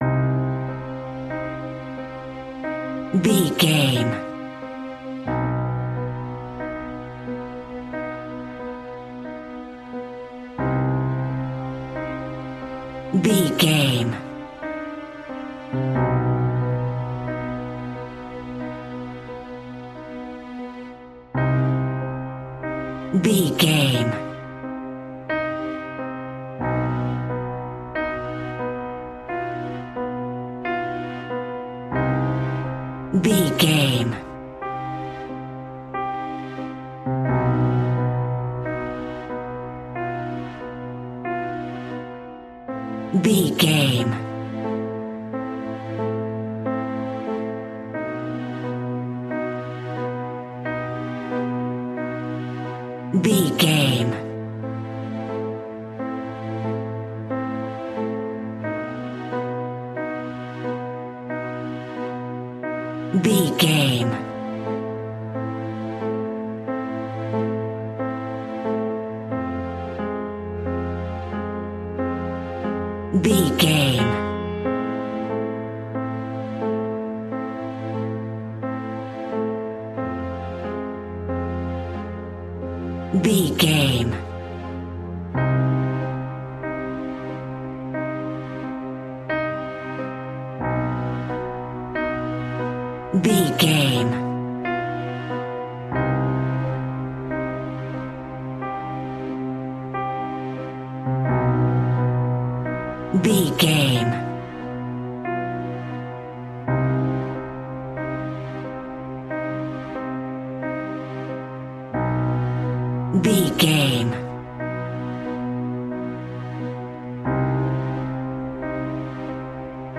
Piano Haunting Story.
Aeolian/Minor
Slow
ominous
haunting
eerie
strings
creepy
horror music